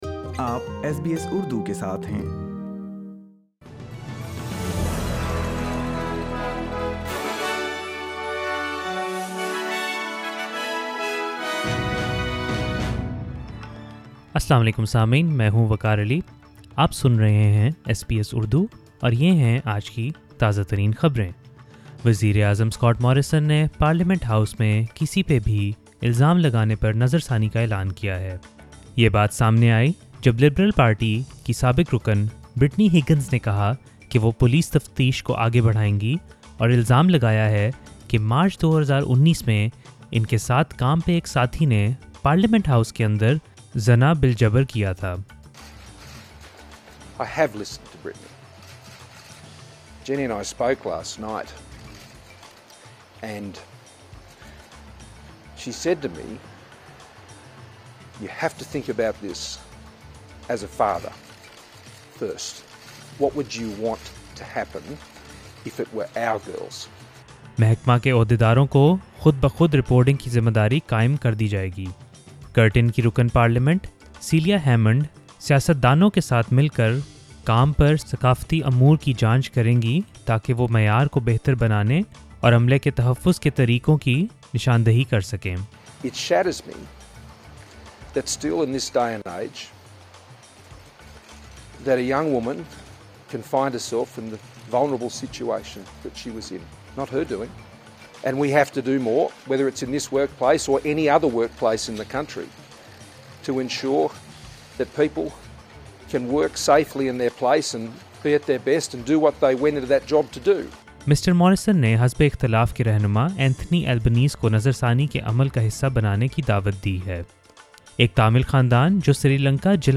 سنئے اردو خبریں پوڈکاسٹ میں۔